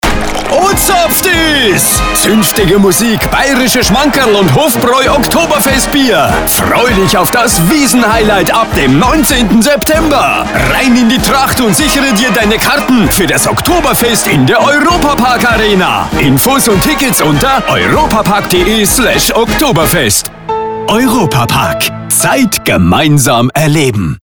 Dabei setzen wir konsequent auf echte Sprecherinnen und Sprecher – keine KI-Stimmen.
Emotional, wohnlich, inspirierend.